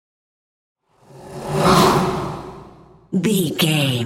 Whoosh airy creature
Sound Effects
Atonal
scary
ominous
eerie
whoosh